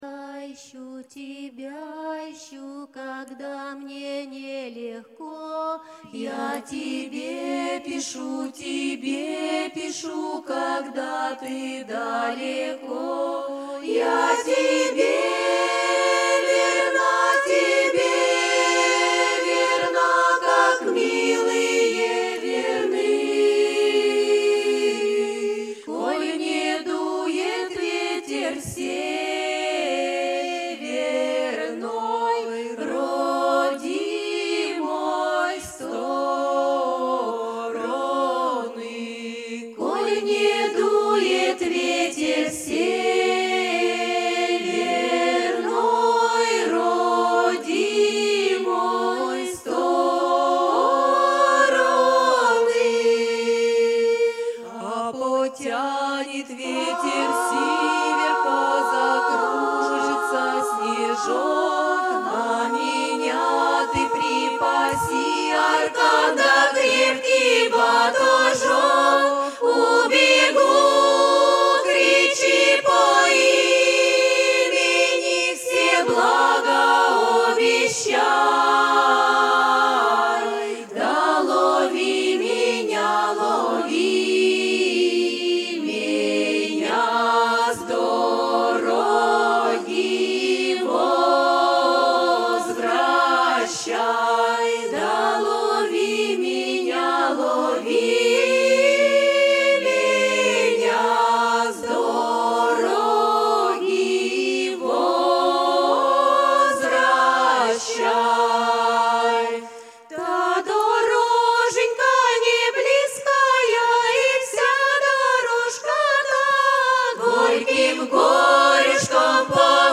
С концерта, гарнитуры, не полный состав, самодеятельный. С гарнитурами настроиться, конечно, нереально. Всё время что-то торчит, где-то подтарчивает... В итоге хоровый звук тяжко получить. Вложения Сударушка - Ветер северный.mp3 Сударушка - Ветер северный.mp3 4,6 MB · Просмотры: 834